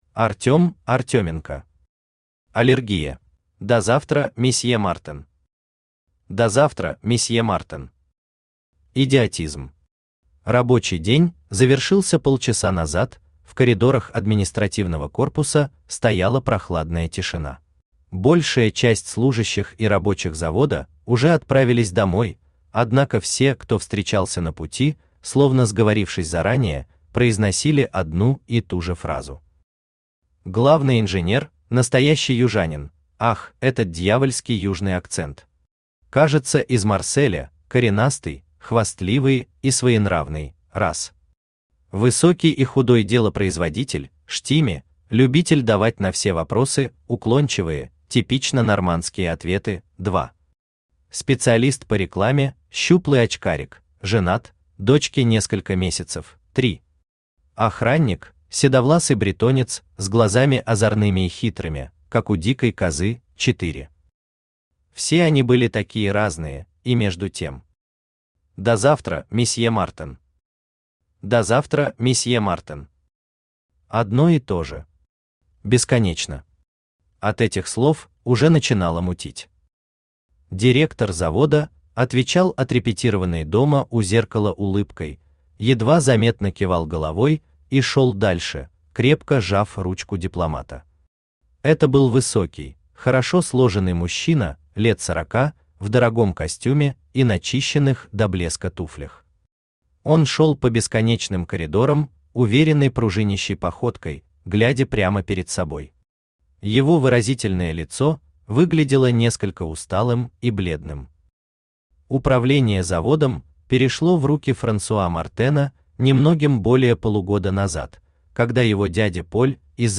Aудиокнига Аллергия Автор Артем Артеменко Читает аудиокнигу Авточтец ЛитРес. Прослушать и бесплатно скачать фрагмент аудиокниги